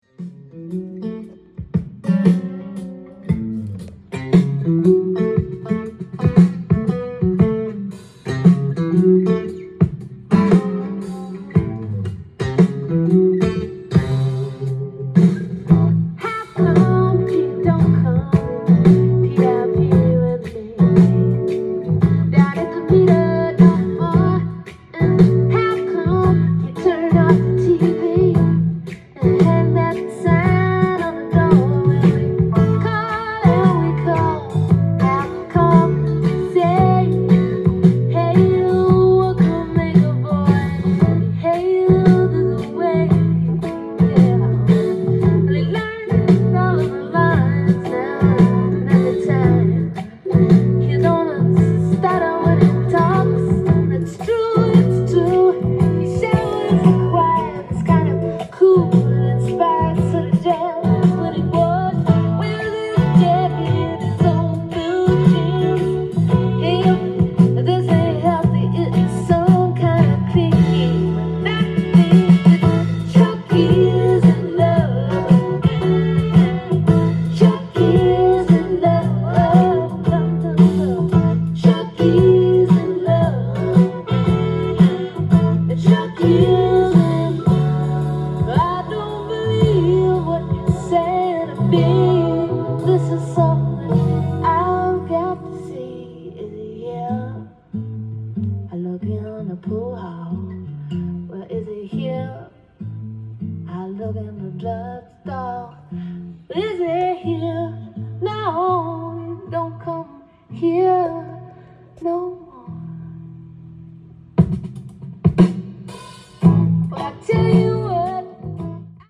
店頭で録音した音源の為、多少の外部音や音質の悪さはございますが、サンプルとしてご視聴ください。
アコースティックでエレガントなAOR名盤！